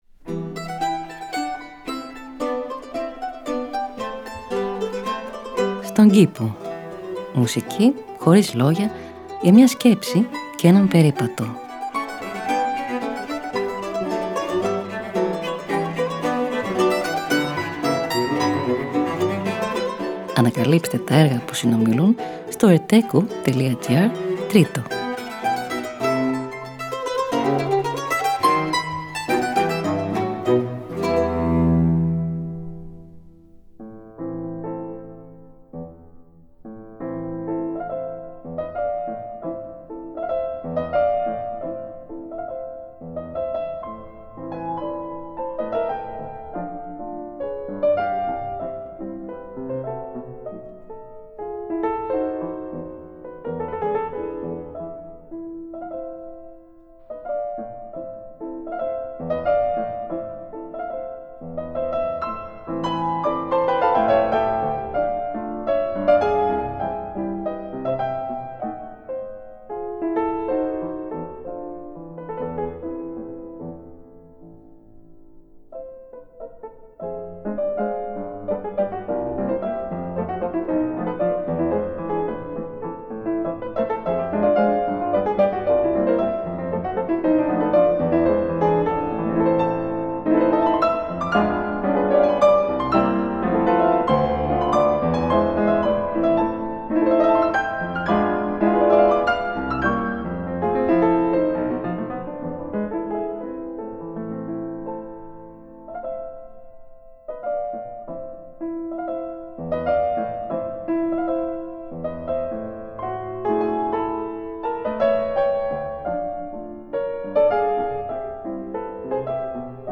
Μουσική Χωρίς Λόγια για μια Σκέψη και έναν Περίπατο.
Arrange for mandolin and continuo